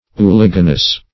Search Result for " uliginous" : The Collaborative International Dictionary of English v.0.48: Uliginose \U*lig"i*nose`\, Uliginous \U*lig"i*nous\, a. [L. uliginosus, fr. uligo, -inis, moisture, fr. uvere to be moist.]